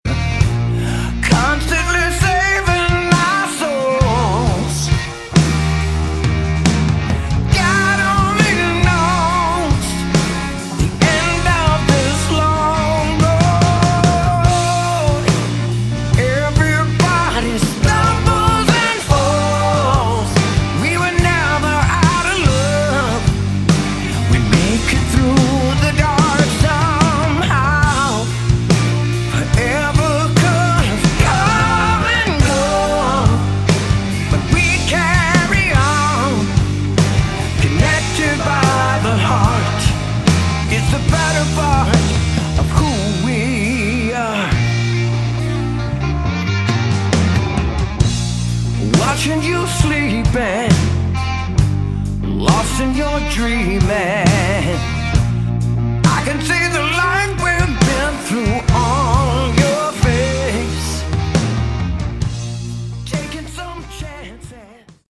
Category: Melodic Rock
bass
vocals
drums
guitars